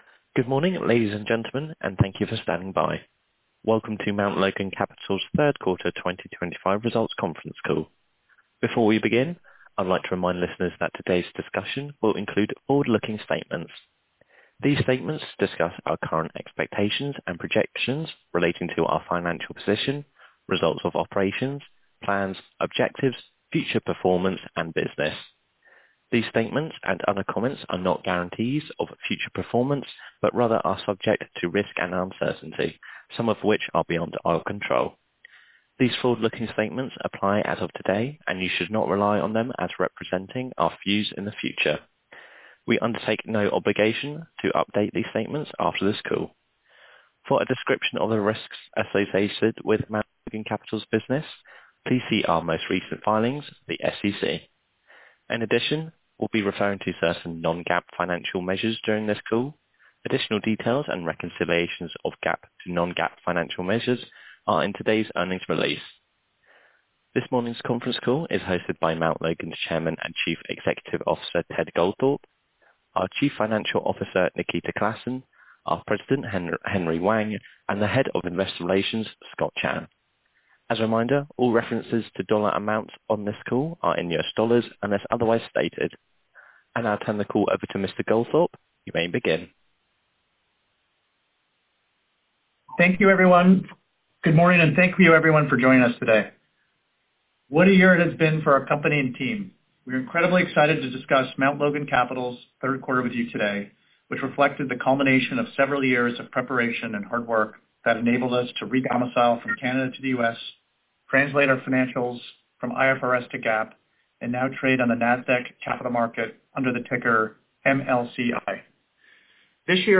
MLC+Q3+2025+Earnings+Call.mp3